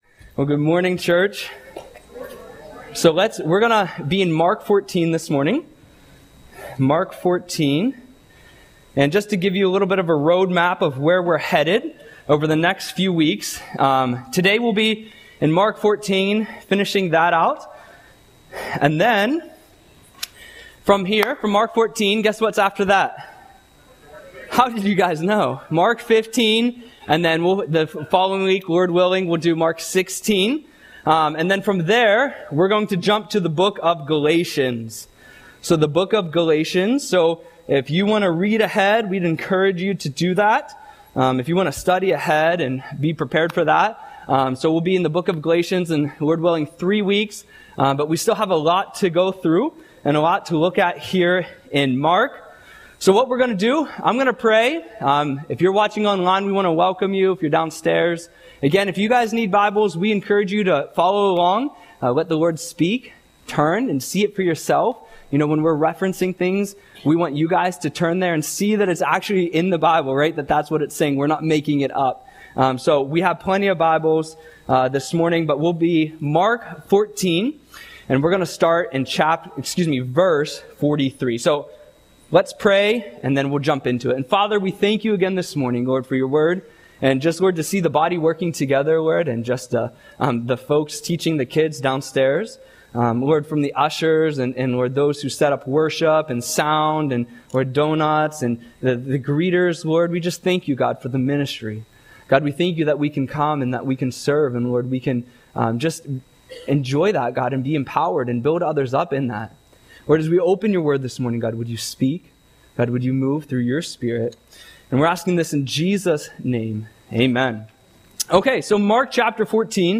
Audio Sermon - April 27, 2025